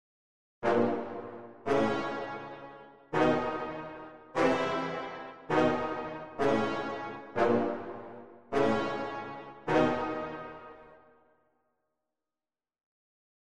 Звуки подозрительные, музыка
Погрузитесь в атмосферу тайны и саспенса с нашей коллекцией подозрительной музыки и звуков.
Для подозрительного момента